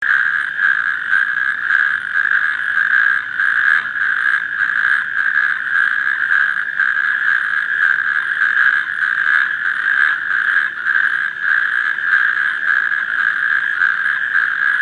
Звук жабы
Но, не смотря на это, звук жабы похож на крики лягушек.
Камышовая жаба:
kamyshovaja-zhaba.mp3